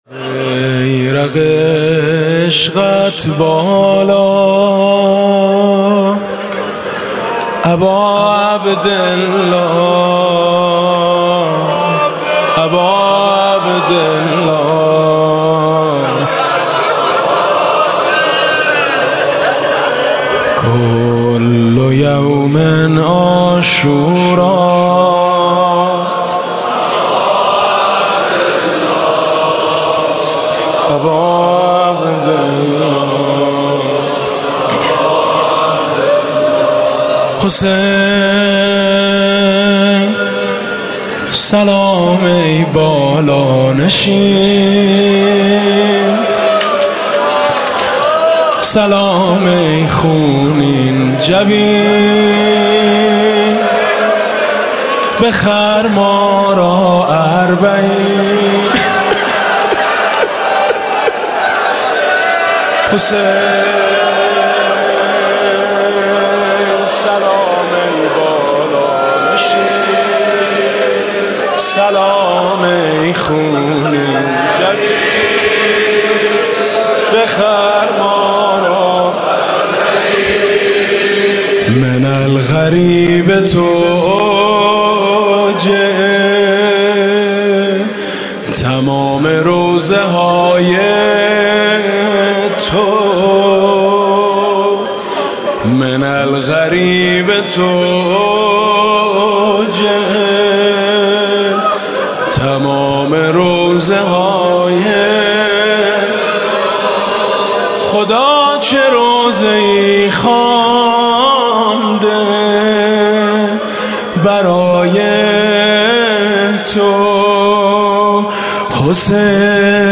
«محرم 1396» (شب اول) زمزمه: بیرق عشقت بالا اباعبدالله